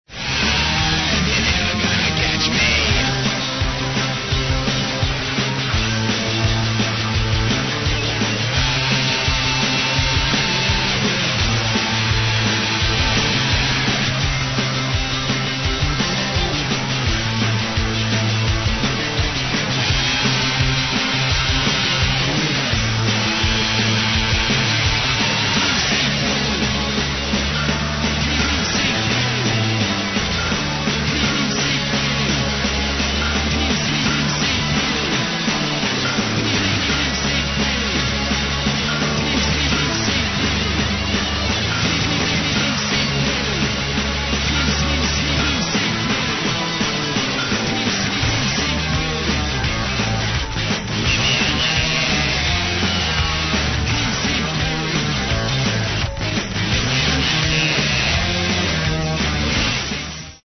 アメリカ、マサチューセッツのローウェルのパンクバンド。
ギター３人にSaxとボーカルもやるドラマーの４人組。
ソリッドでかっこいいオルタナバンドといったサウンド。